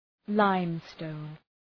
Προφορά
{‘laım,stəʋn}